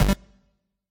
piece_set.ogg